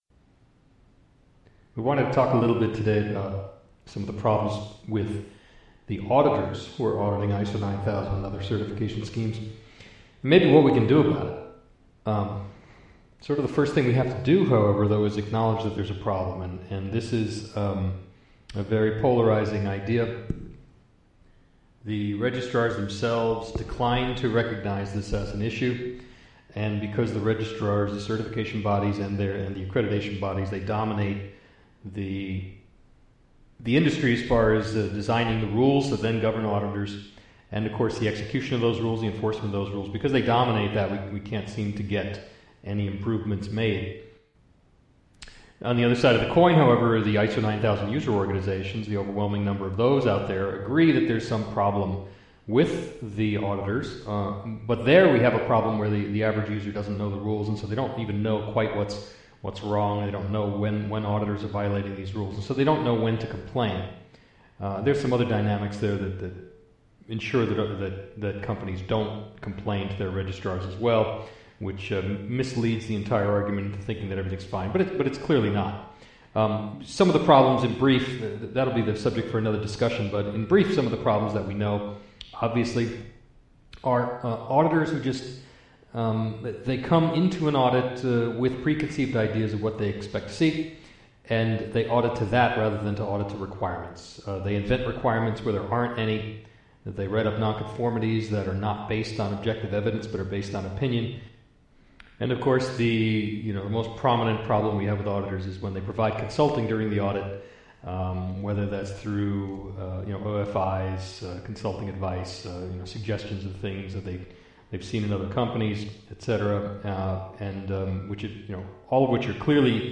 From a talk given at a closed event in February 2015, this discussion looks at why ISO 9001 certification body auditors routinely perform poorly, and how the current lack of training, as well as psychological factors which arise during the audit experience, ensure auditors will remain poor performers. Includes steps which must be taken to reverse this trend.